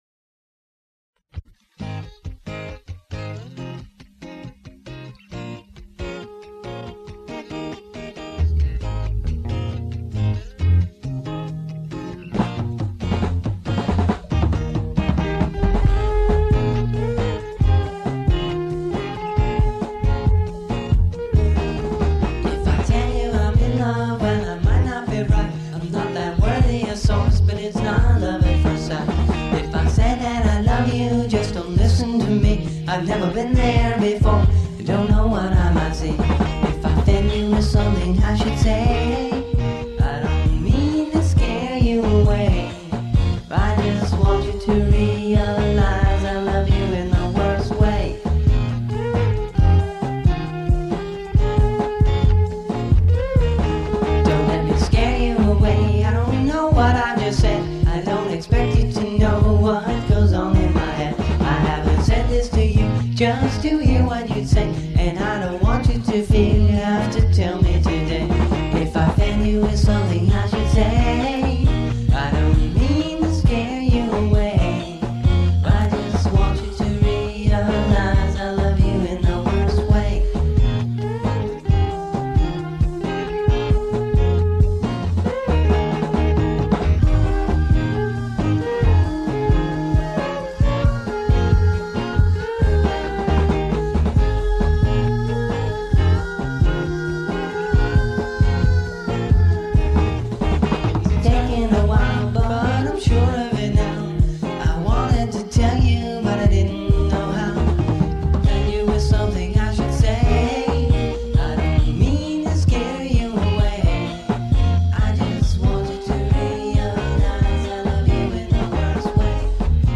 between 2 cassette recorders...